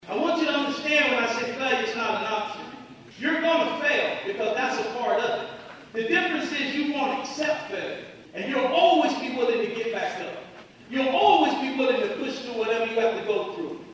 spoke to students at the event, urging the graduates to not accept failure while also accepting that failure will happen.